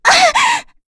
Frey-Vox_Damage_03.wav